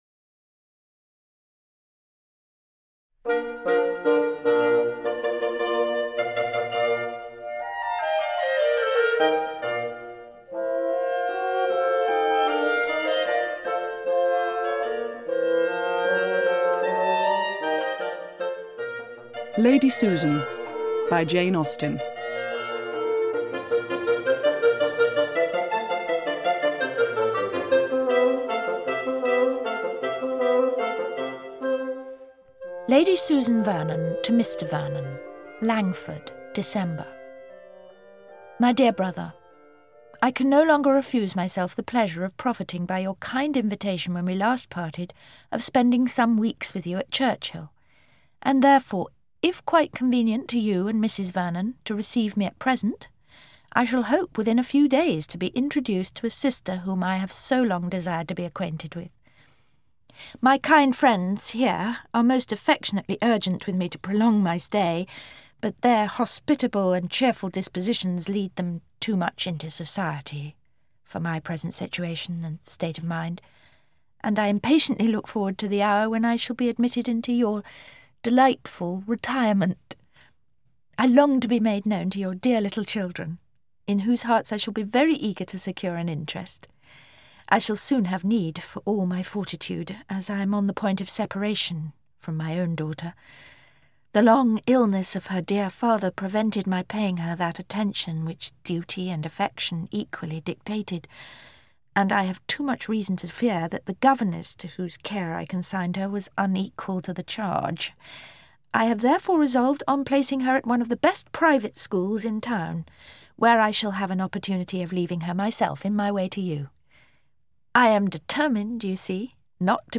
Lady Susan (EN) audiokniha
Ukázka z knihy
Less known than Austen’s six great later novels, it demonstrates the wit and sharp observations of Jane Austen – and is shown at its best in audiobook form, with different actresses presenting real characters as they read their letters.